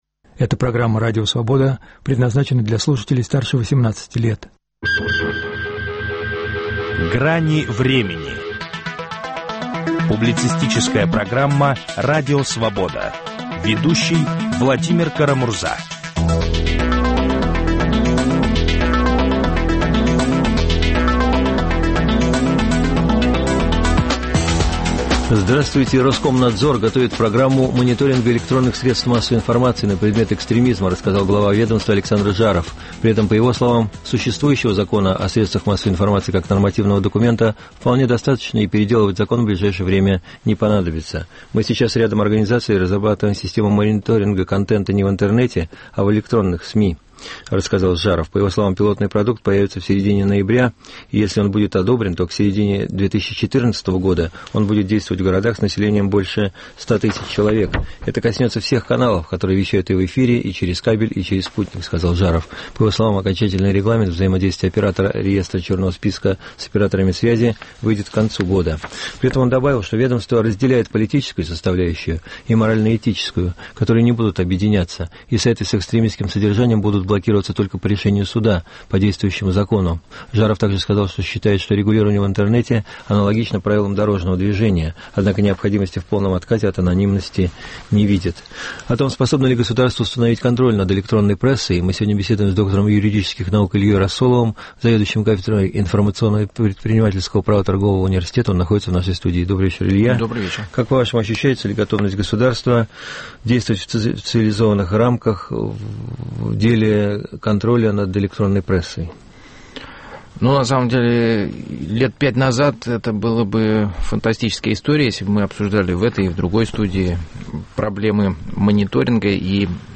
беседуем